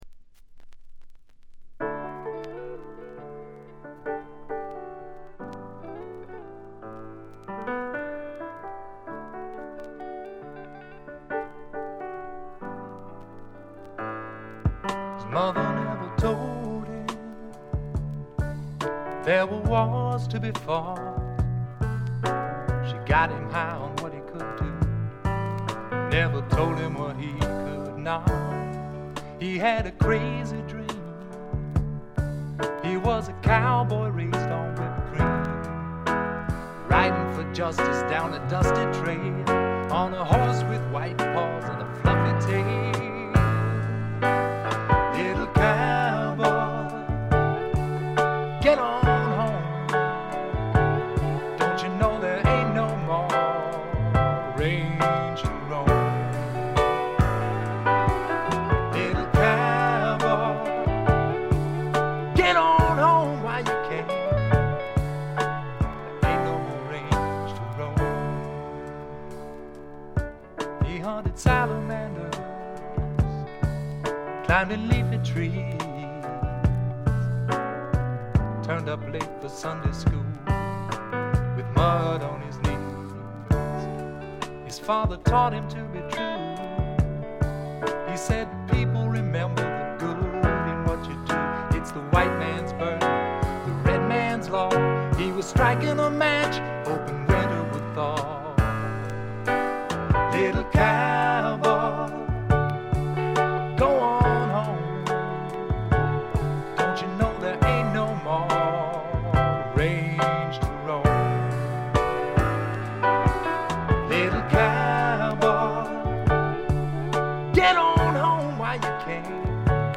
軽微なチリプチ。散発的なプツ音少し。
シンプルなバックに支えられて、おだやかなヴォーカルと佳曲が並ぶ理想的なアルバム。
試聴曲は現品からの取り込み音源です。